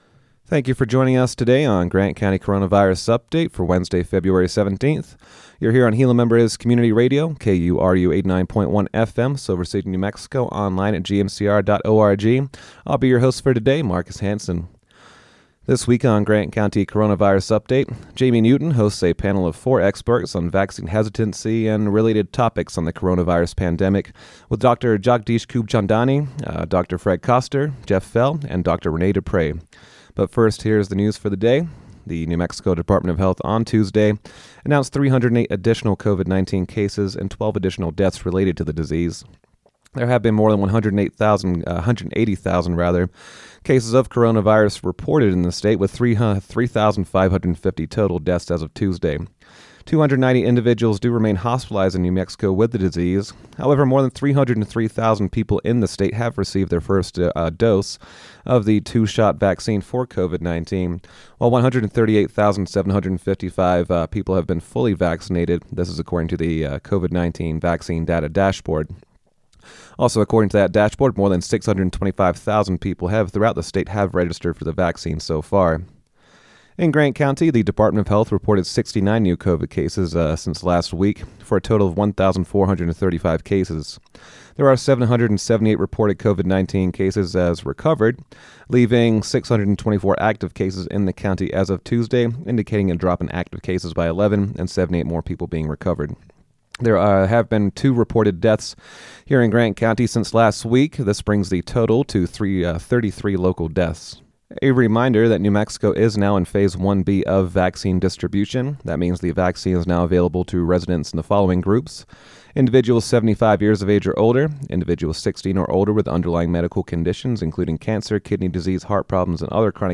a panel of four experts on vaccination hesitancy